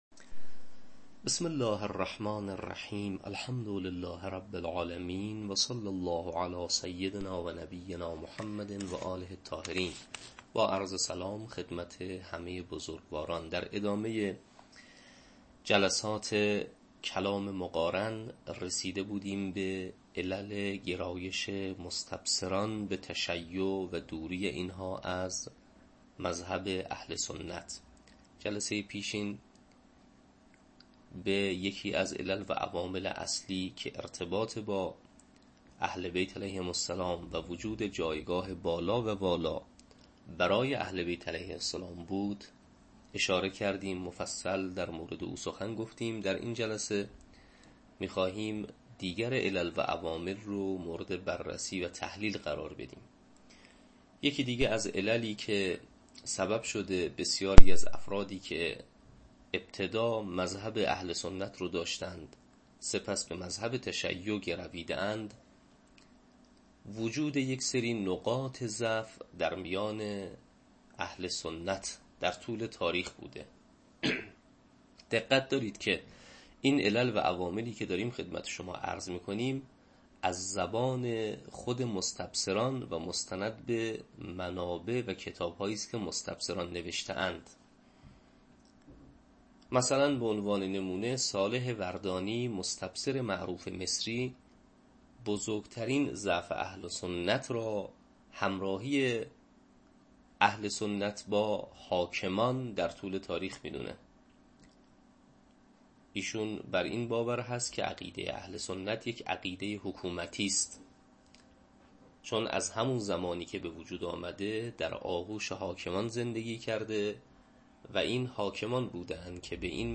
تدریس کلام مقارن